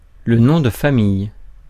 Ääntäminen
France: IPA: /nɔ̃.də.fa.mij/ Paris